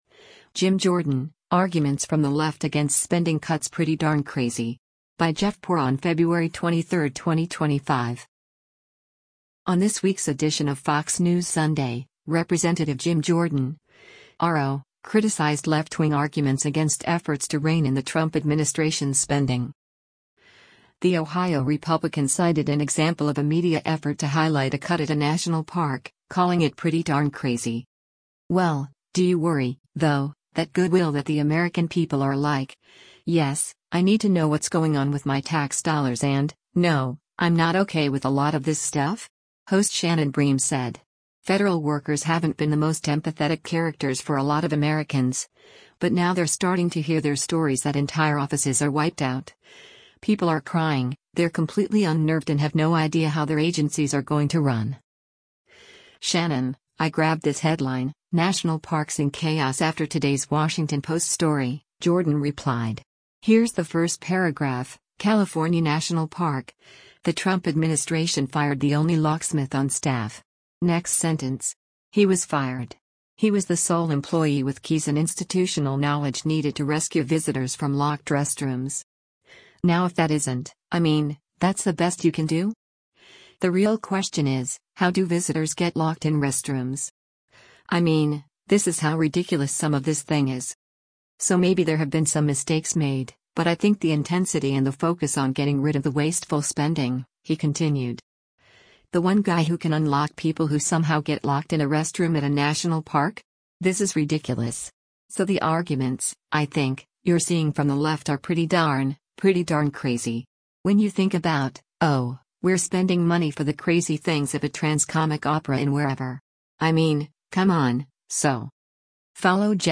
On this week’s edition of Fox News Sunday, Rep. Jim Jordan (R-OH) criticized left-wing arguments against efforts to rein in the Trump administration’s spending.